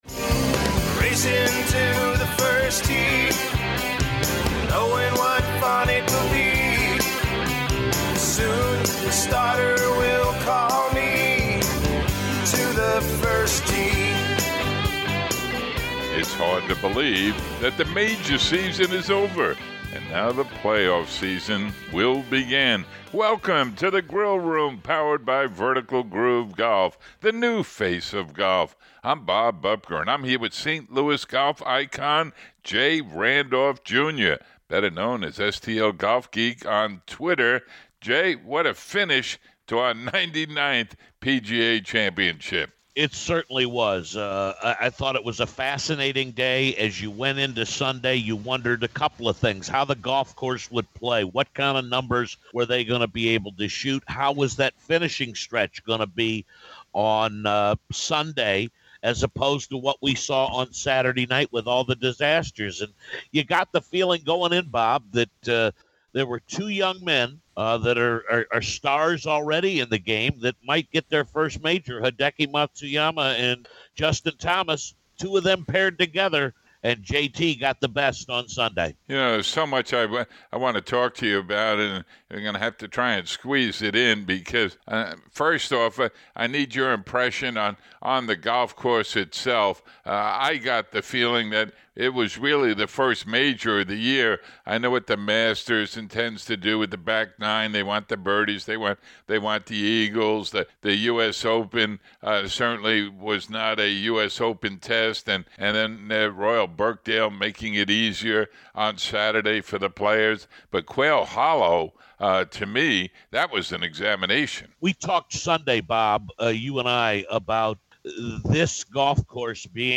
A feature interview